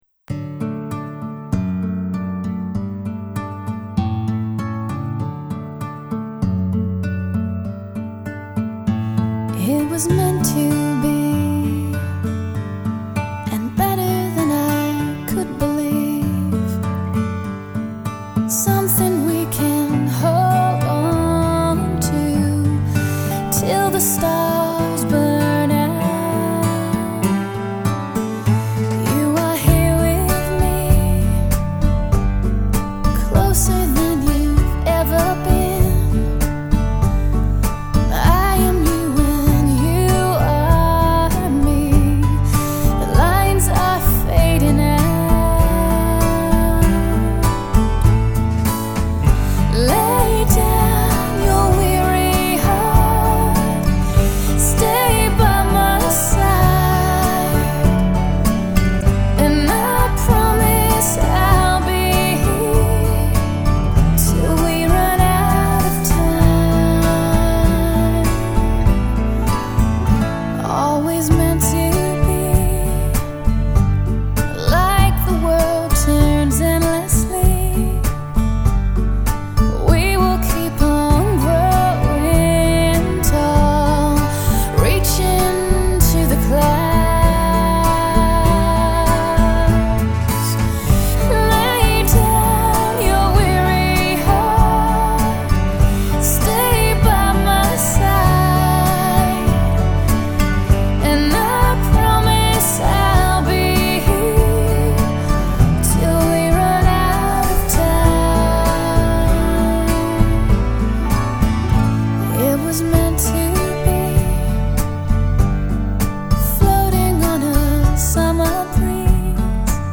with her haunting celtic voice